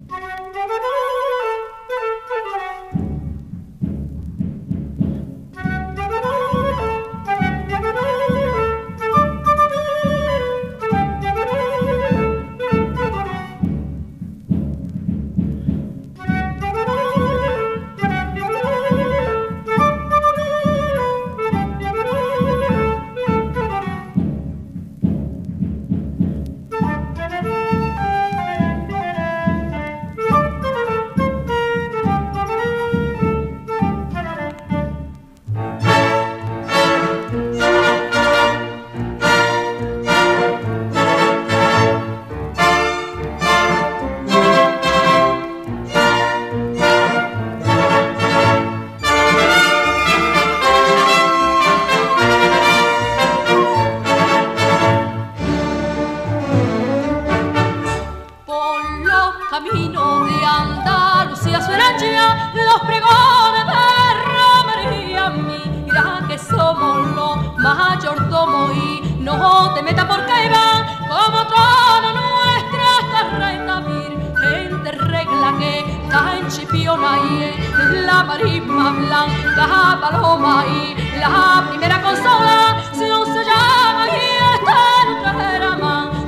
la joven cantaora